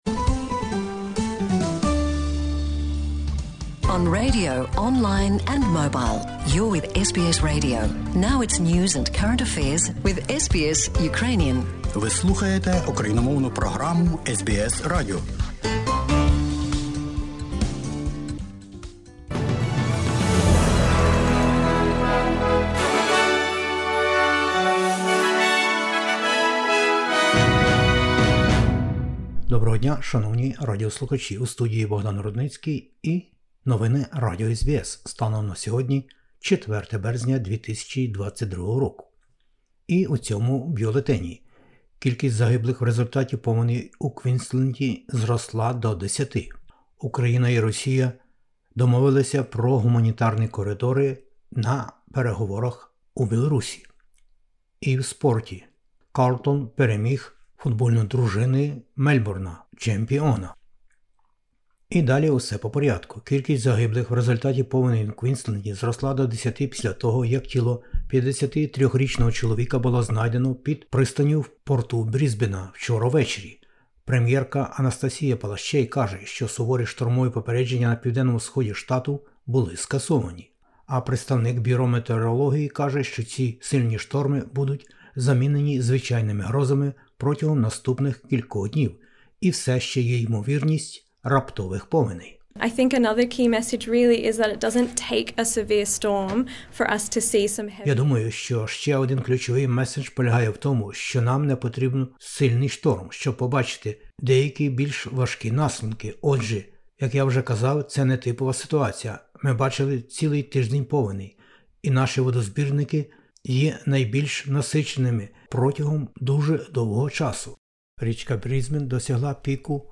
SBS новини українською 04/03/2022